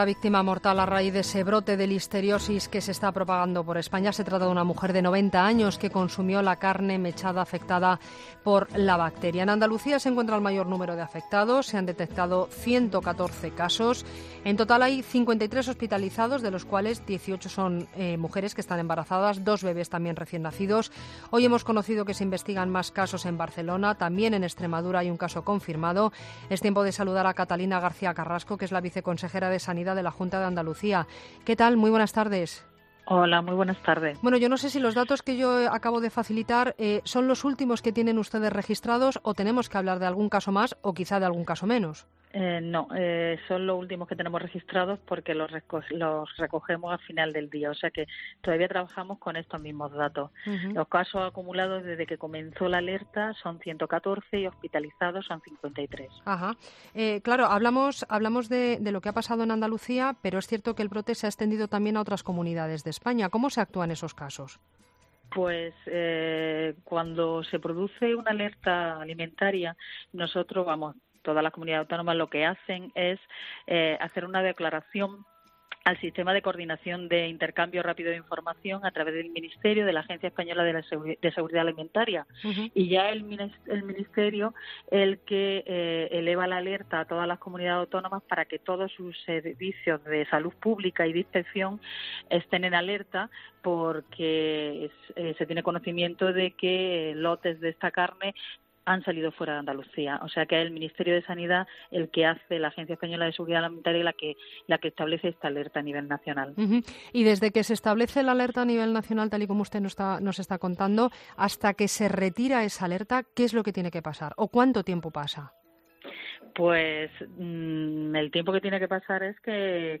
AUDIO: La viceconsejera de sanidad de la Junta de Andalucía nos cuenta en La Linterna la situación en la comunidad por el brote de Listeriosis